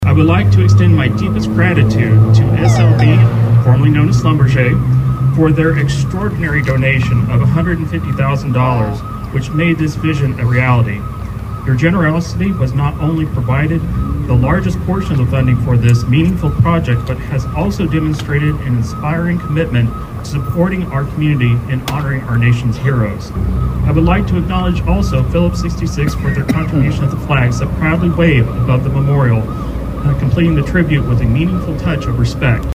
Several people came out to Veterans Park in west Bartlesville Monday to officially mark the opening of the new memorial, which features flag poles for each military branch, monuments to battles fought and a special reflection bench dedicated to the person who set the wheels in motion for the memorial, former City Councilor Billie Roane.